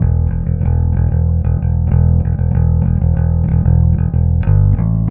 Trem Trance Bass 01.wav